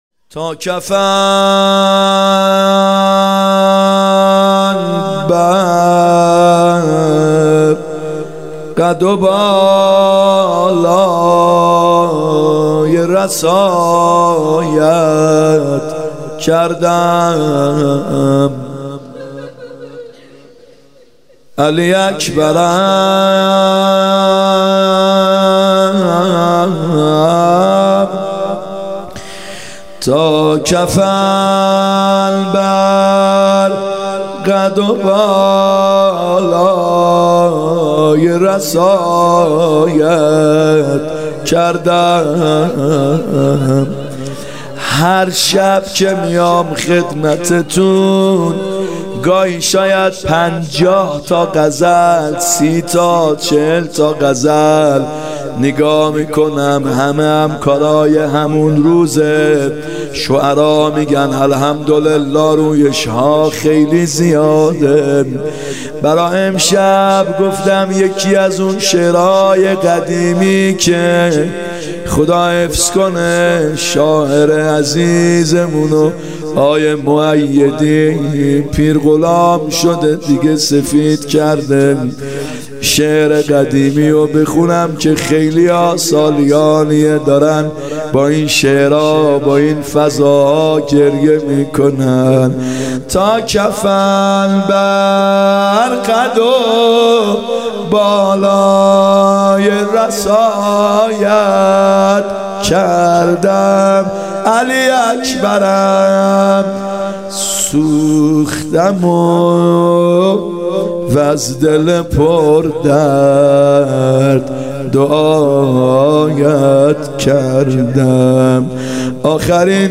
محرم 94(هیات یا مهدی عج)